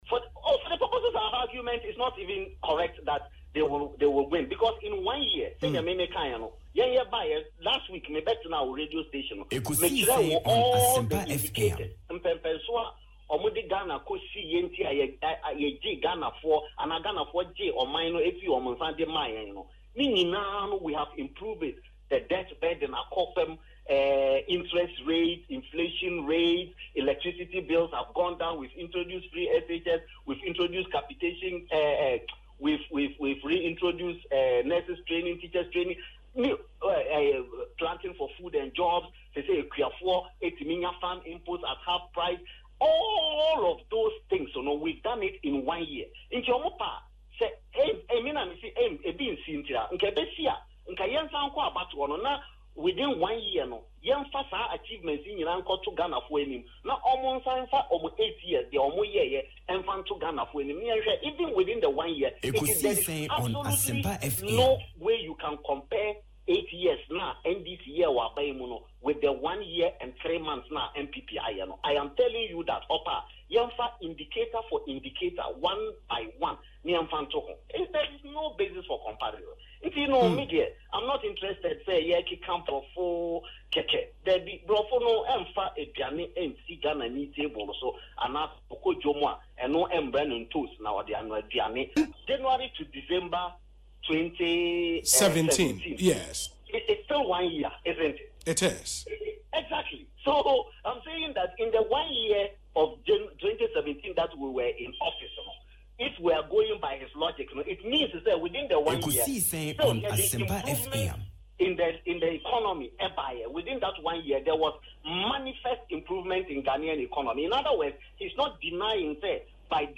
But speaking on Asempa FM’s Ekosii Sen Monday, Mustapha Hamid said the NPP will not sweat over Mr. Mahama’s candidature but rather pay attention to any of the new entrants.